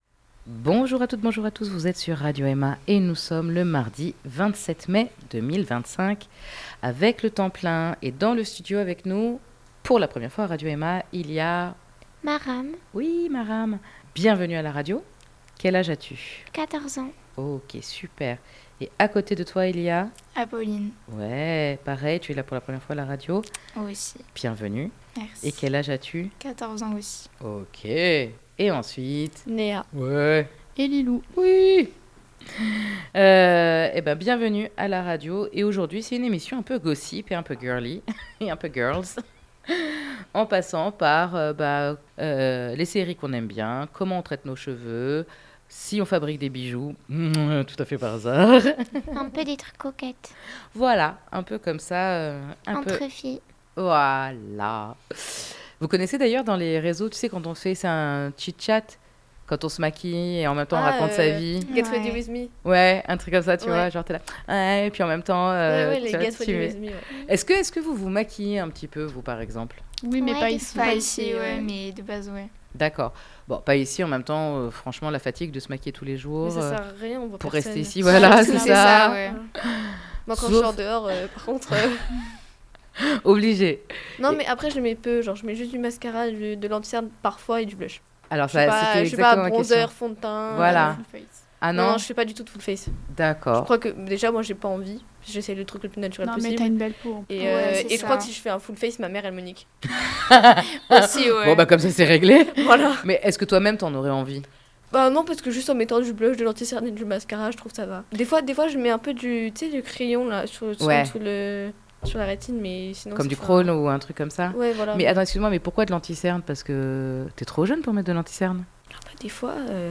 Aujourd'hui, nous discutons maquillage, séries télé, tendances capillaires, bref un tchit tchat entre girls où chacune se raconte.